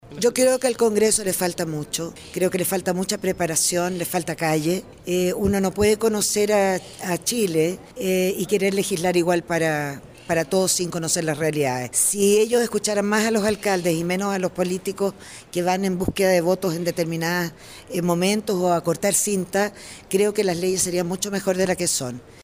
Finalmente, la alcaldesa de María Pinto, Jessica Mualim, hizo un llamado al congreso a “tener calle” y votar los proyectos pensando en la ciudadanía.